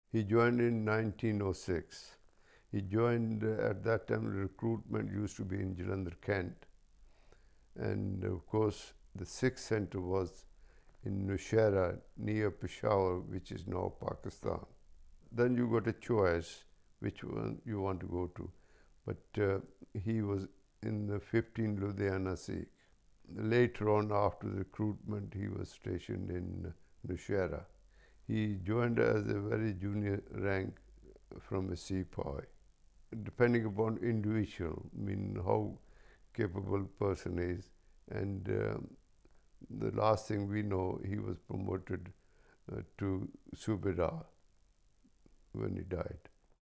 Location: Maidenhead, Berkshire